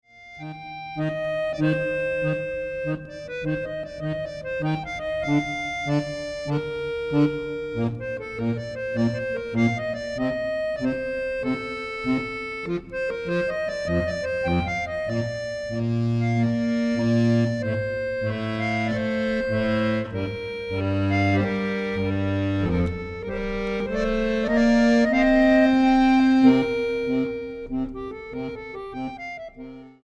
acordeón clásico